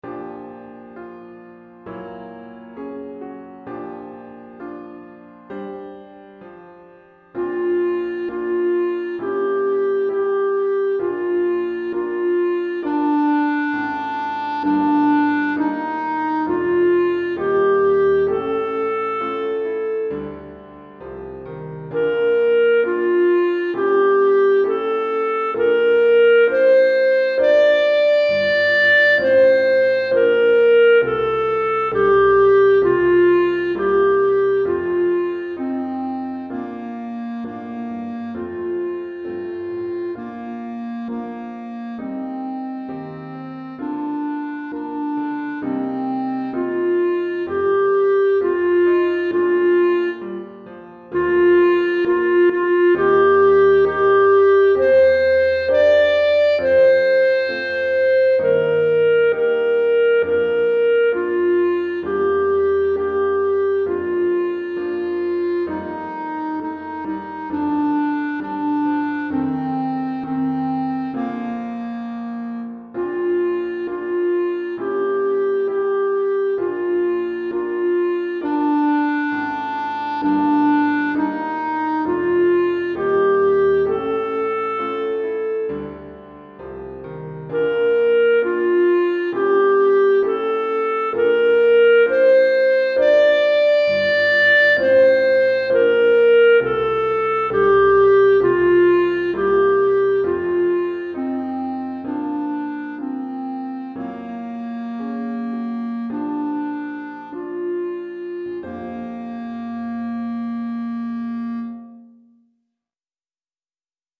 pour clarinette et piano DEGRE CYCLE 1